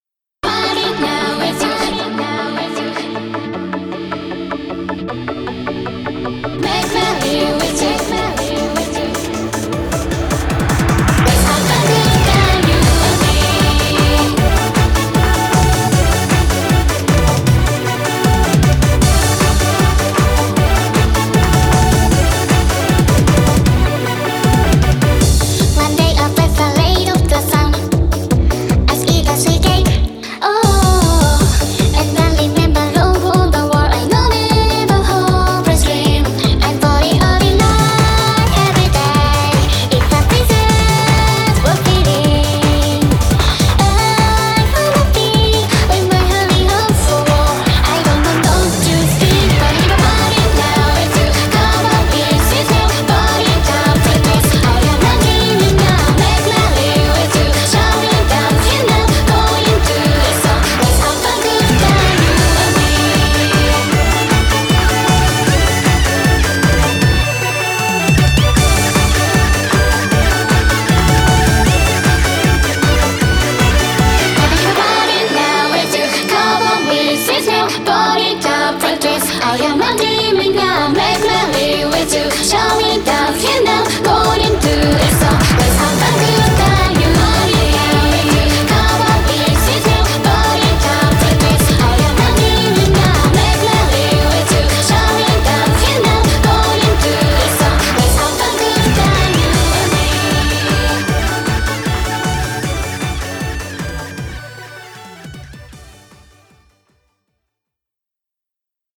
BPM155
Audio QualityPerfect (High Quality)
Genre: EUROBEAT.